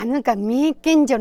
Aizu Dialect Database
Final intonation: Falling
Location: Aizumisatomachi/会津美里町
Sex: Female